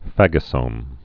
(făgə-sōm)